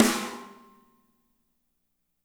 -MEDSNR2I -R.wav